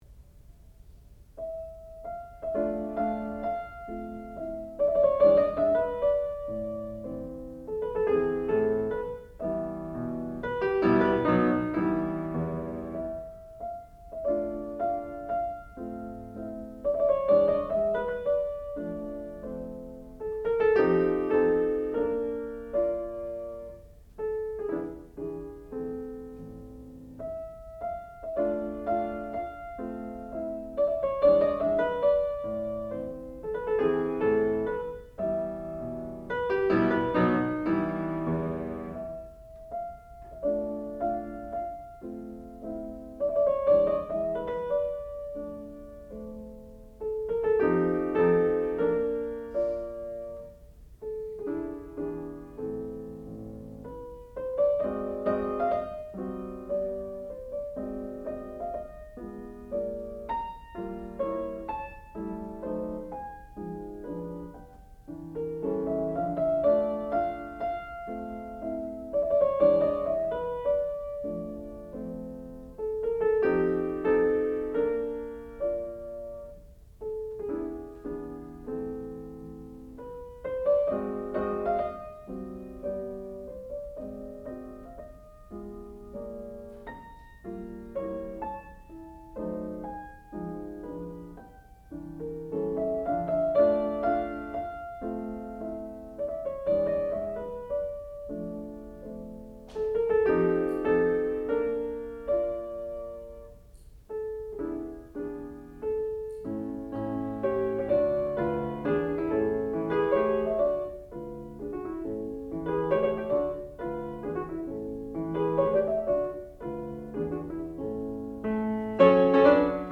sound recording-musical
classical music
Master Recital